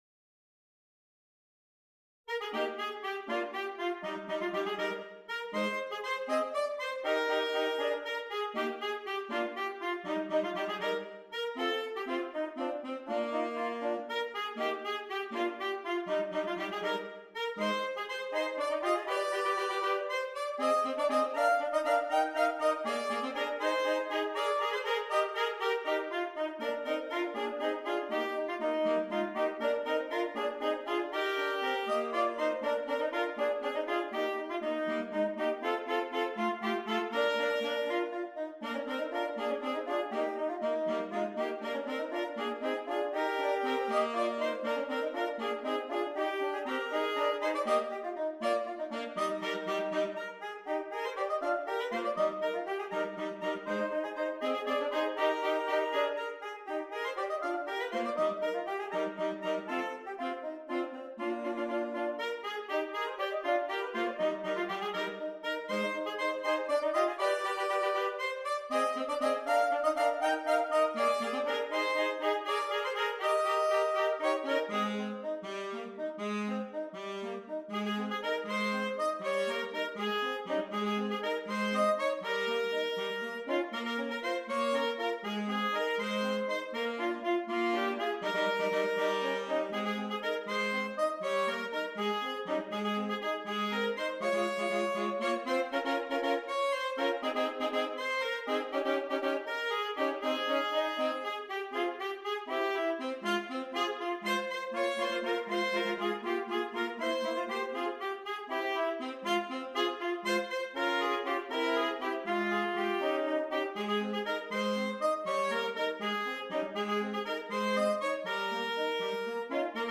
3 Alto Saxophones